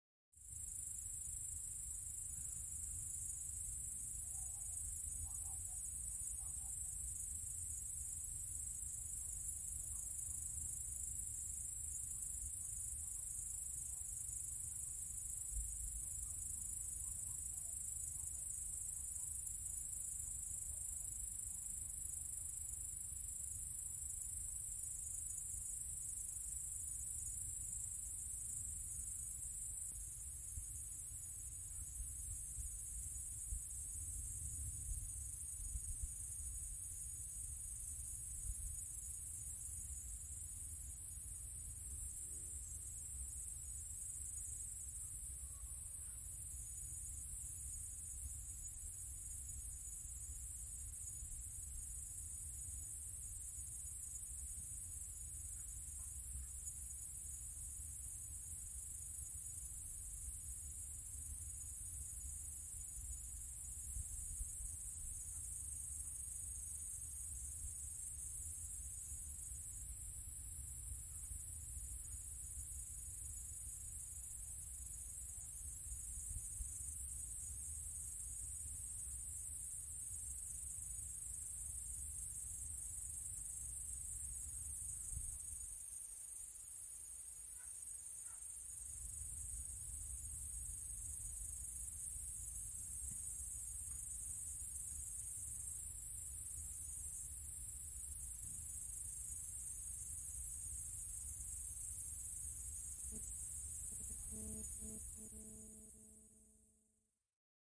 夜晚的吱吱声
描述：我在森林里，我记录了crekets让我知道你是否正在使用它
标签： 蟋蟀 昆虫 crekets 性质 现场录音 板球
声道立体声